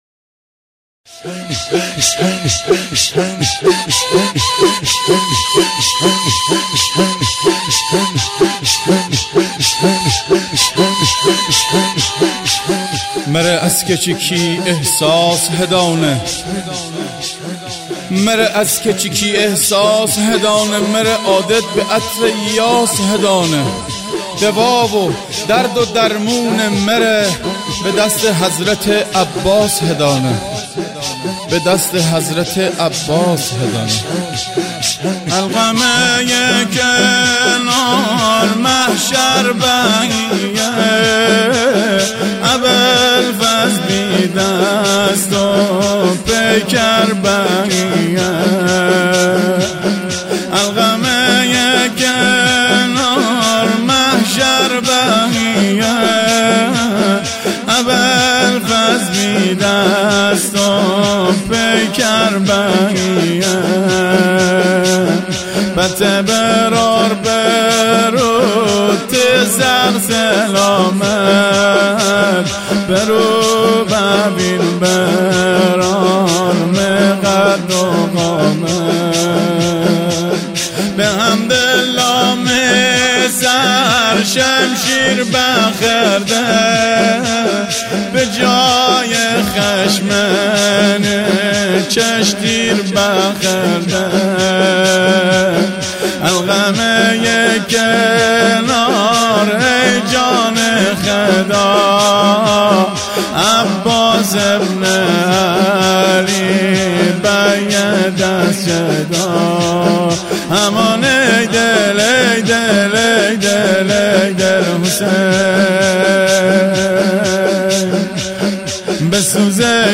شورمازندرانی